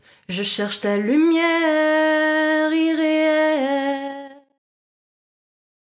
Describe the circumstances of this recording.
Dry: